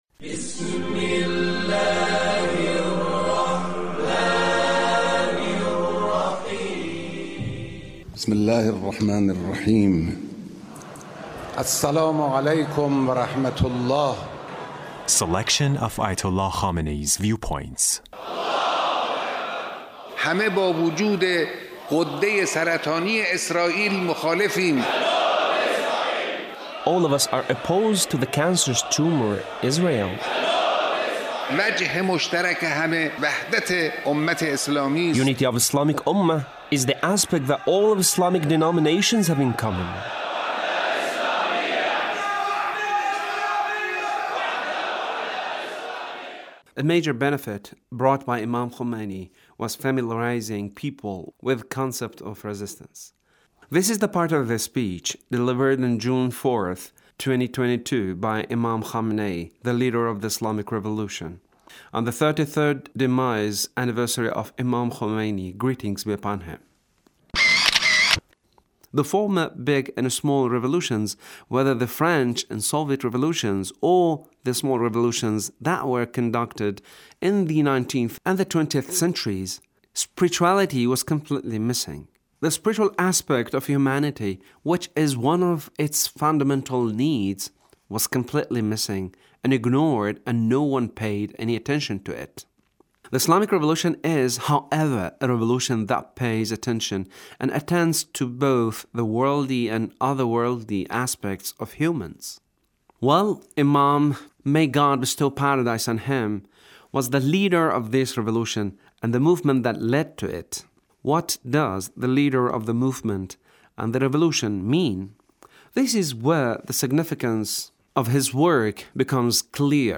Leader's speech